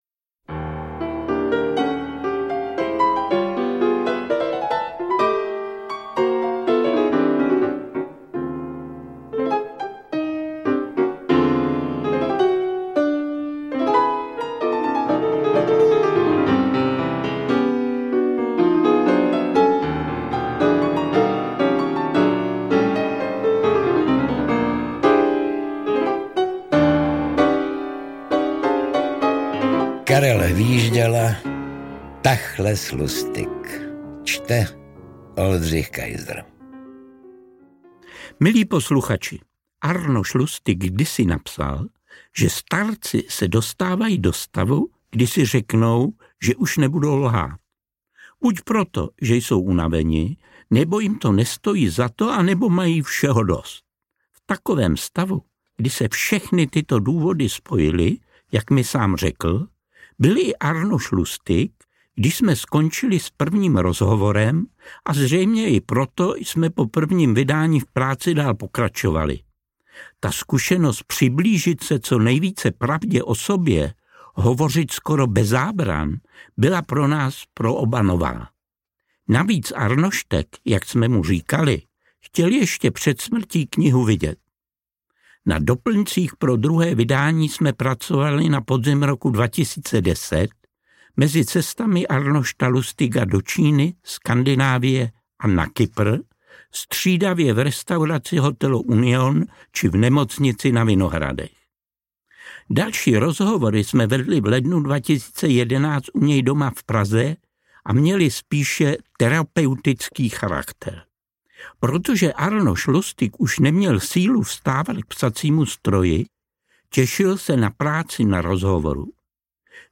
• AudioKniha ke stažení Hvížďala: Tachles, Lustig
Interpreti:  Karel Hvížďala, Oldřich Kaiser